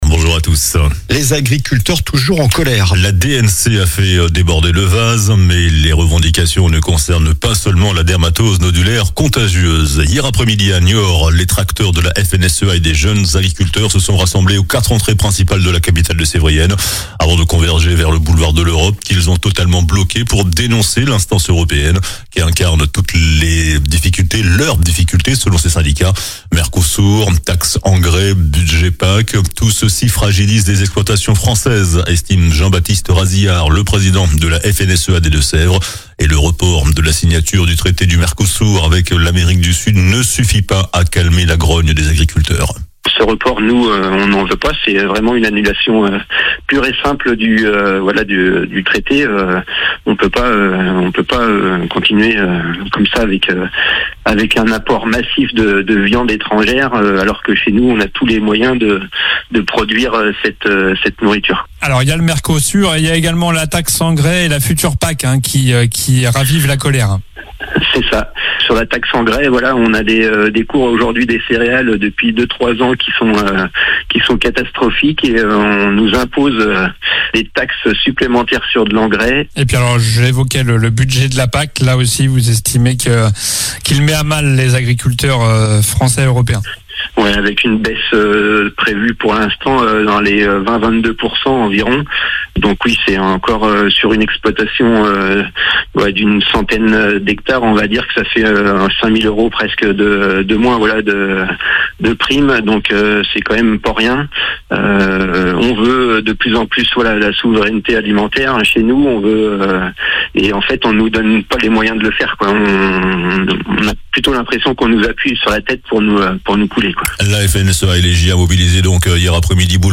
JOURNAL DU SAMEDI 20 DECEMBRE